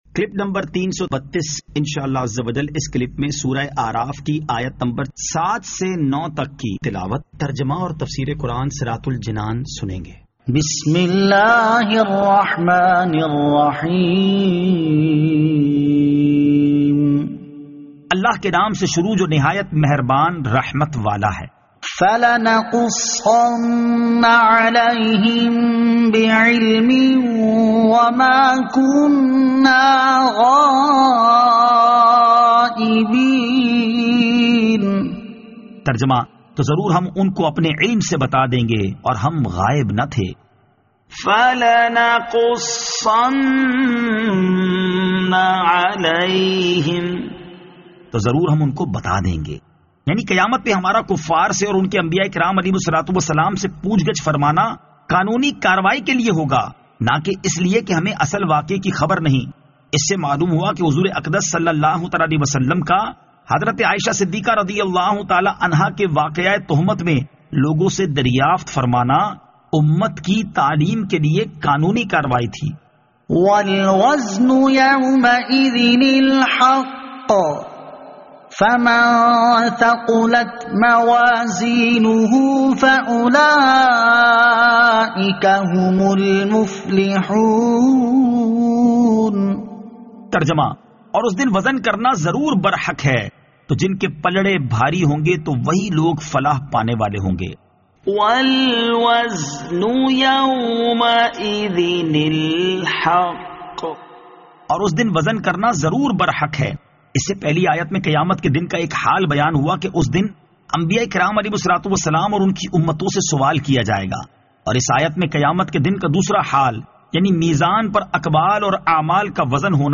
Surah Al-A'raf Ayat 07 To 09 Tilawat , Tarjama , Tafseer